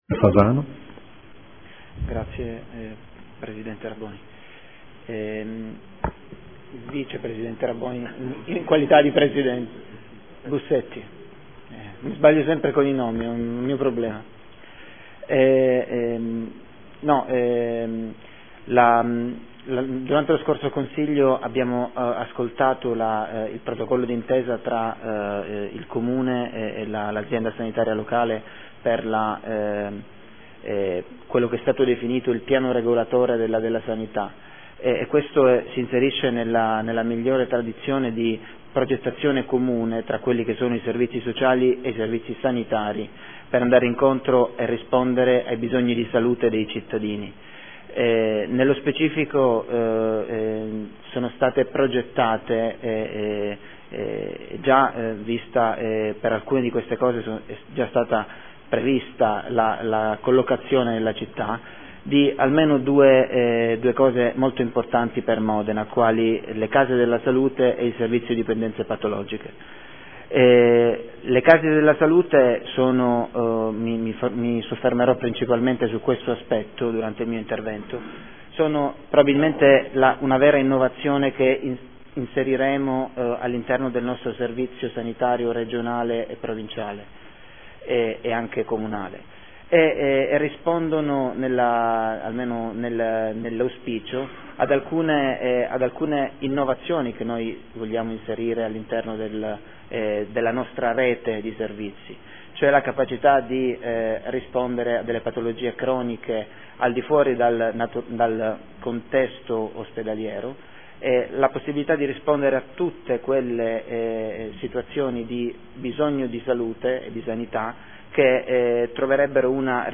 Dibattito su ordini del giorno
Audio Consiglio Comunale